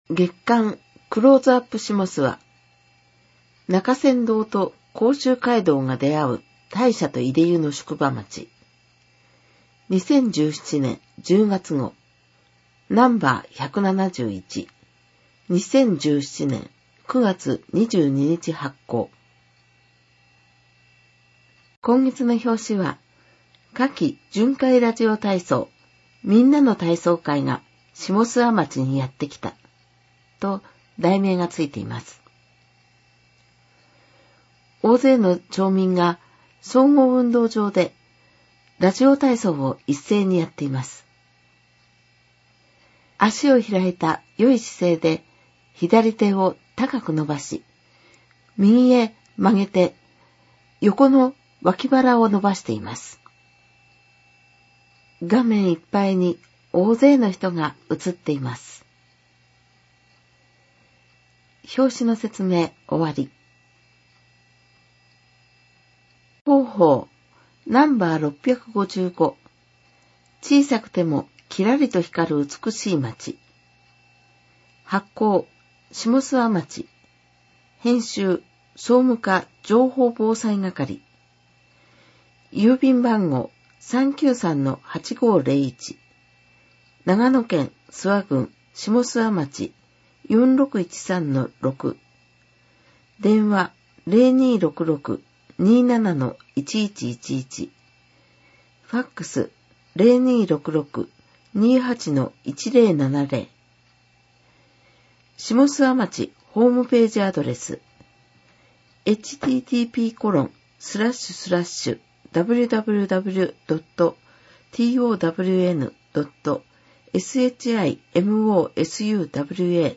ダウンロード （音読版）クローズアップしもすわ2017年10月号 [ mp3 type：15MB ] （音読版）生涯学習521号 [ mp3 type：8MB ] 添付資料を見るためにはビューワソフトが必要な場合があります。